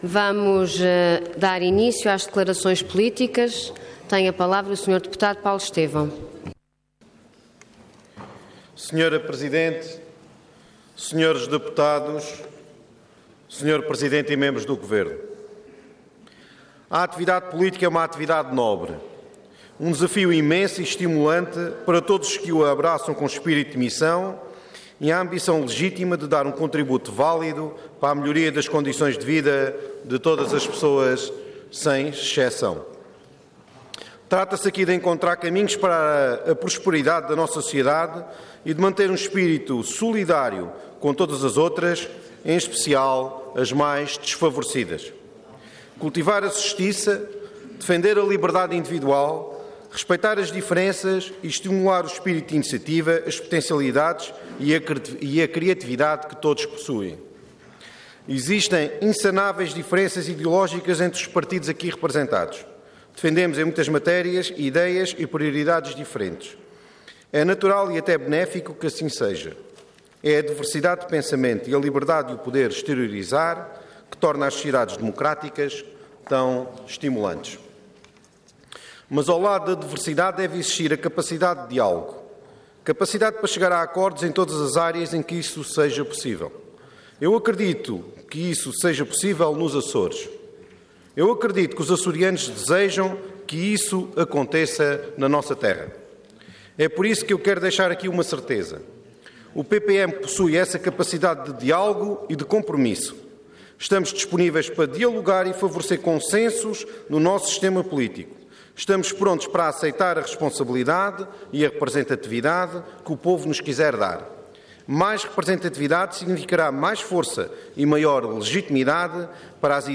Detalhe de vídeo 13 de julho de 2016 Download áudio Download vídeo Processo X Legislatura A Reforma Autonómica Intervenção Declaração Política Orador Paulo Estêvão Cargo Deputado Entidade PPM